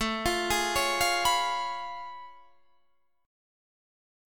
Listen to A+M9 strummed